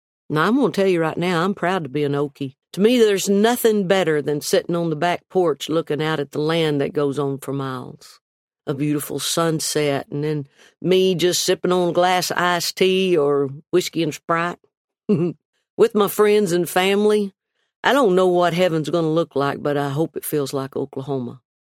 Audio / From the audio version of her new book, Not That Fancy, Reba McEntire is proud to be from Oklahoma.